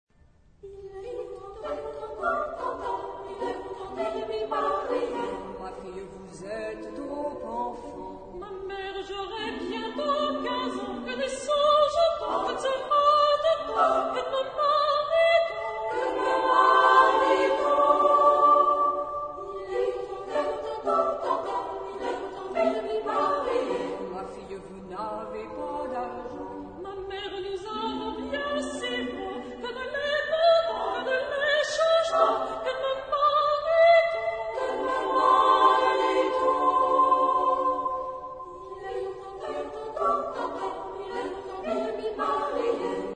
Genre-Stil-Form: weltlich ; Volkstümlich
Chorgattung: SSAA  (4 Frauenchor Stimmen )
Tonart(en): G-Dur